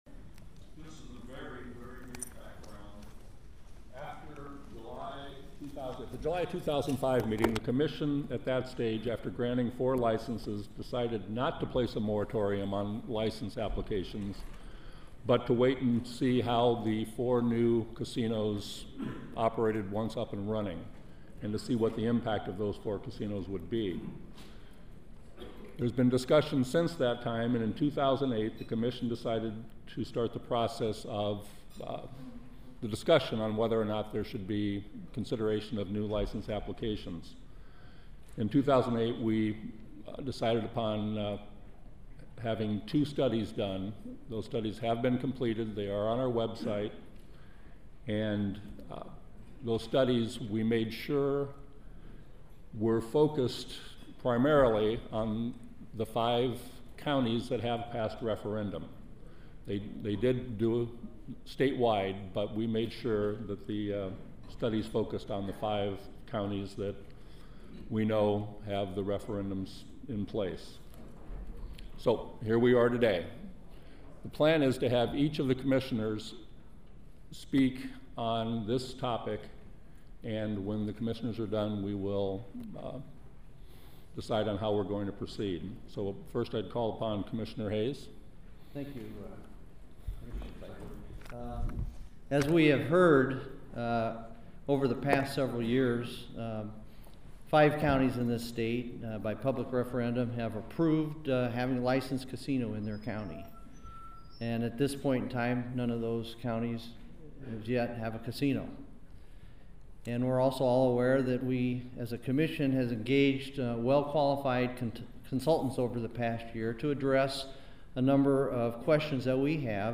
During a meeting this morning in Altoona, Commission chairman Greg Seyfer of Cedar Rapids warned the communities that Iowans don’t want widespread gambling in the state.